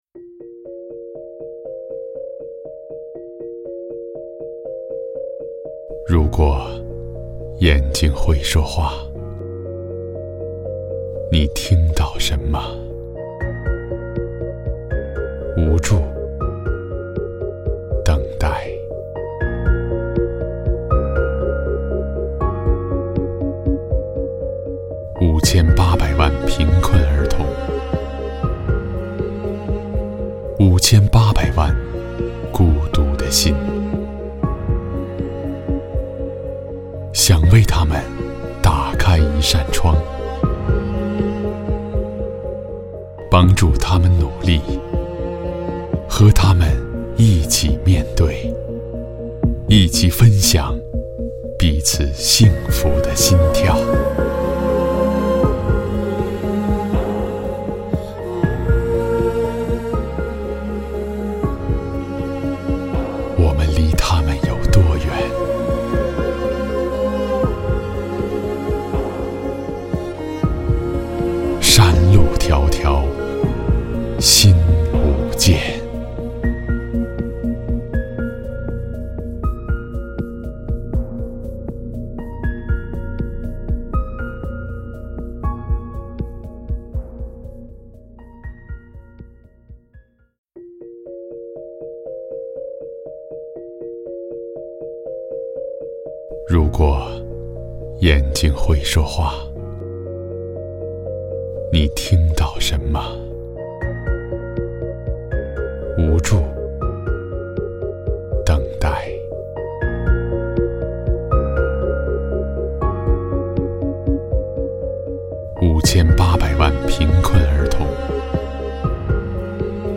国语青年大气浑厚磁性 、沉稳 、积极向上 、时尚活力 、男广告 、600元/条男11 国语 男声 广告 一汽大众 大气浑厚磁性|沉稳|积极向上|时尚活力